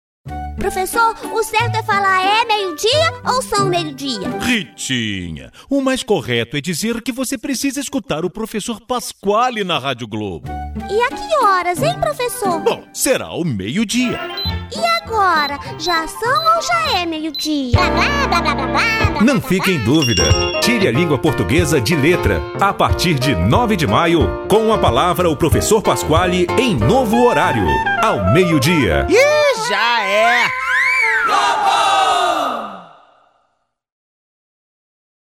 Feminino